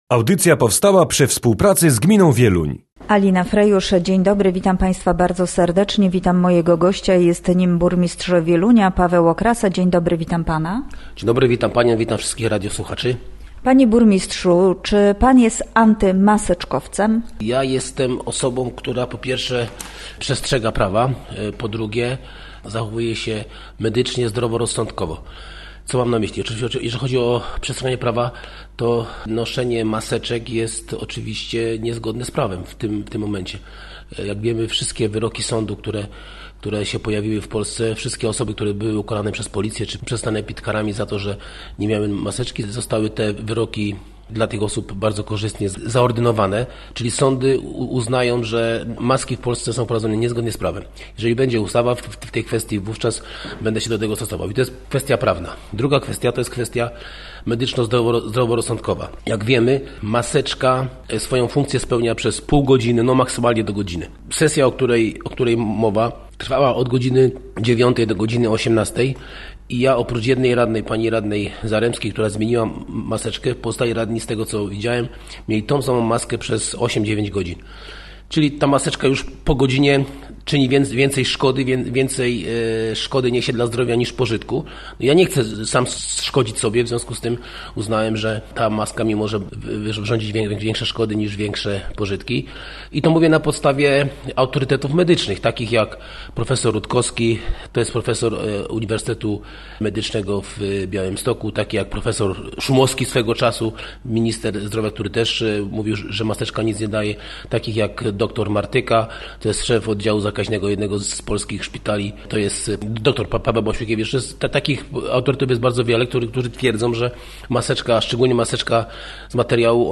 Gościem Radia ZW był Paweł Okrasa, burmistrz Wielunia